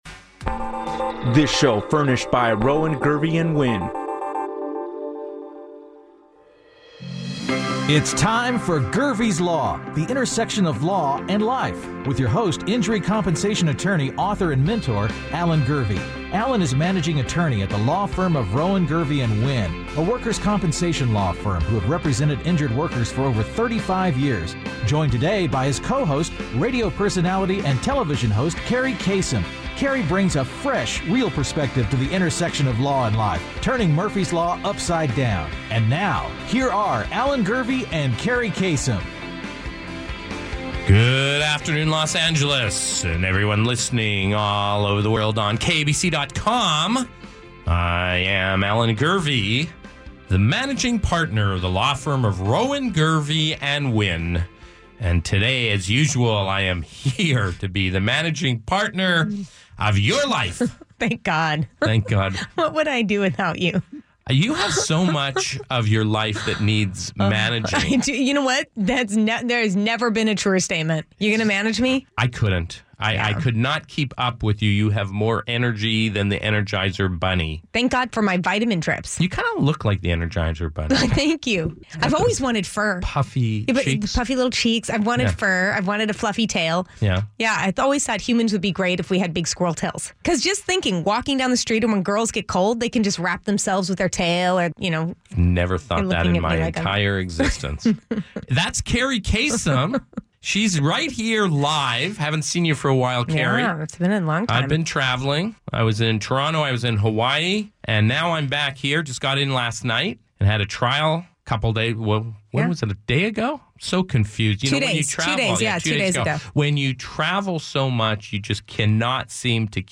Don't miss the sports-meets-law banter